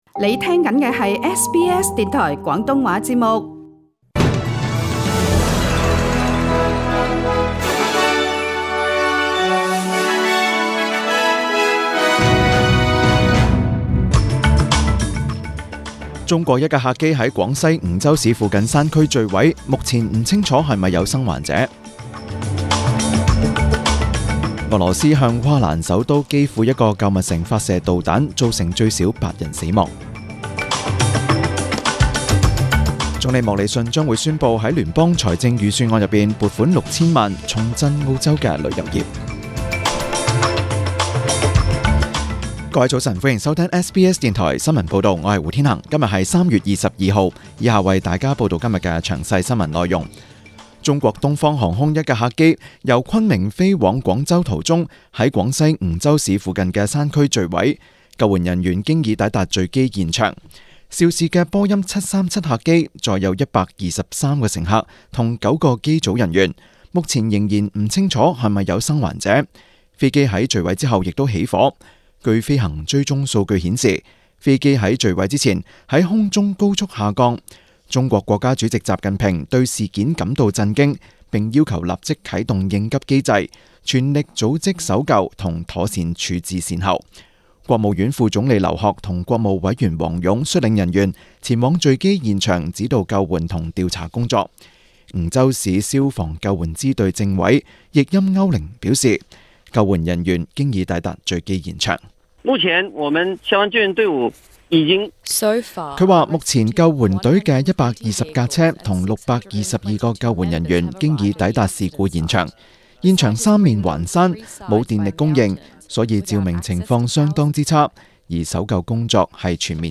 请收听本台为大家准备的详尽早晨新闻。